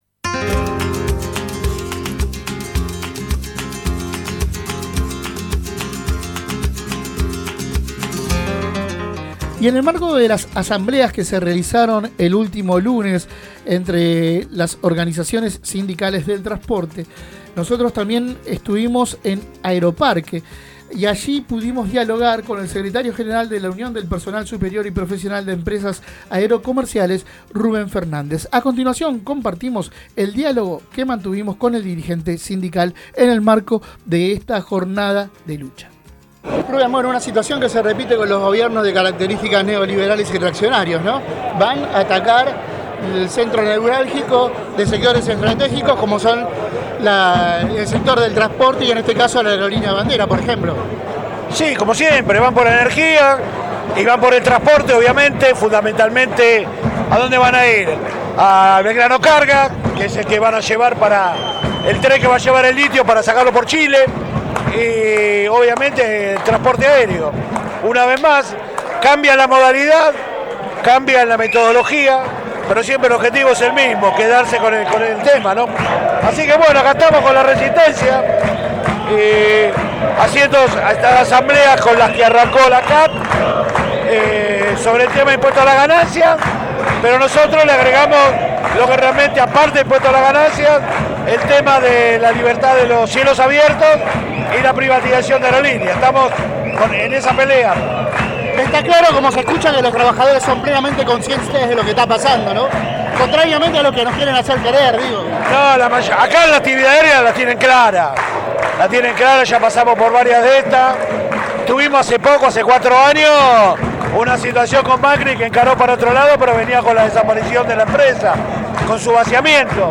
Compartimos la entrevista completa: